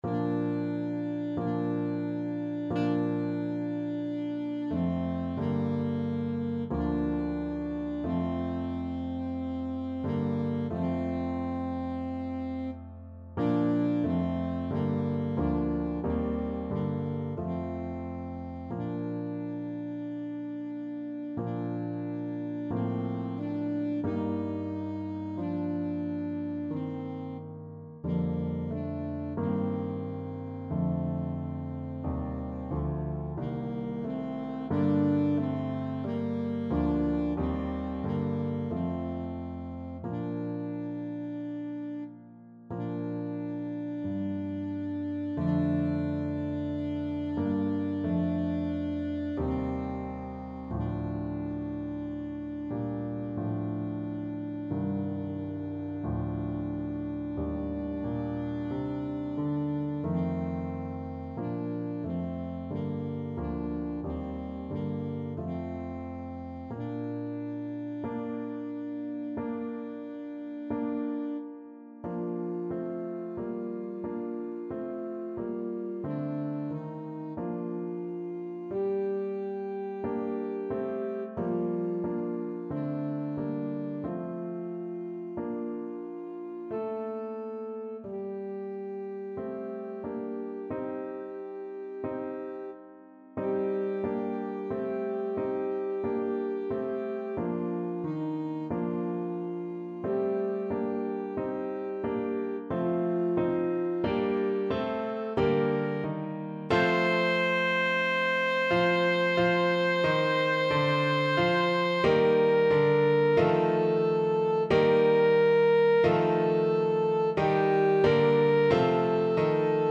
Alto Saxophone version
Alto Saxophone
4/4 (View more 4/4 Music)
Smoothly, not quick =45
Classical (View more Classical Saxophone Music)